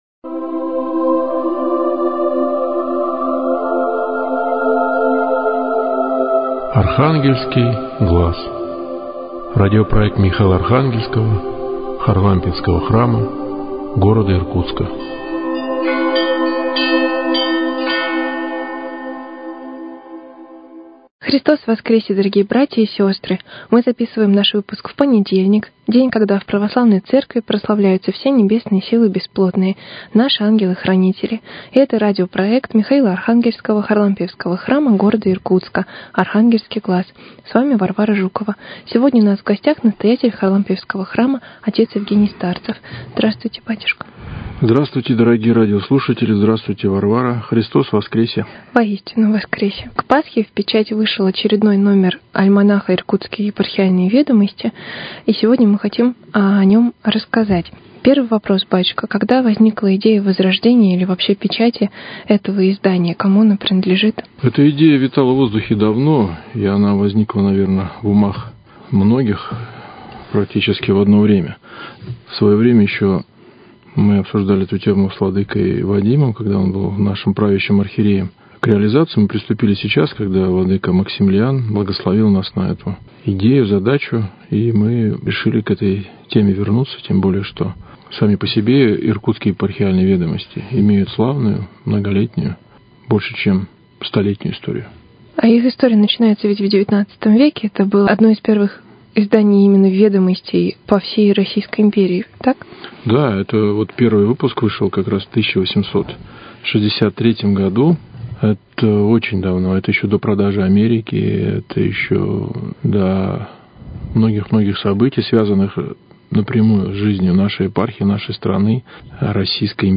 В печать вышел второй номер Иркутских Епархиальных Ведомостей. Беседа